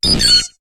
Cri de Tournegrin dans Pokémon HOME.